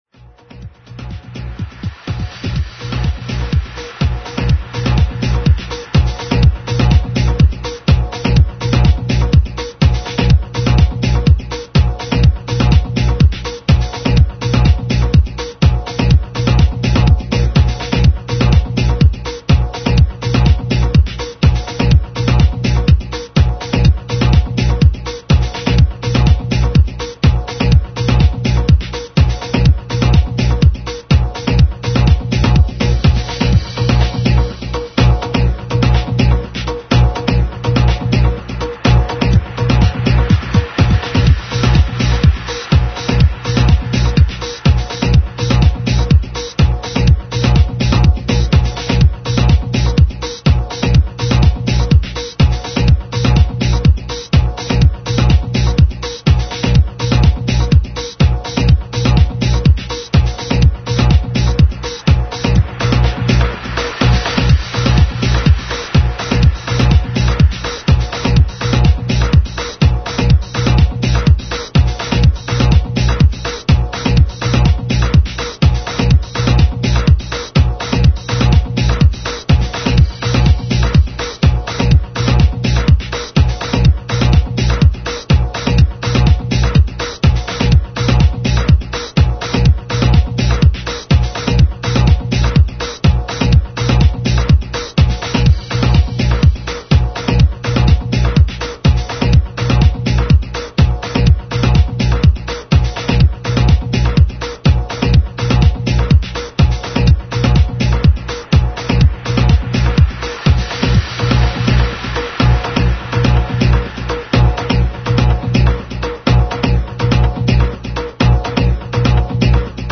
House music, Progressive House, Tech-House music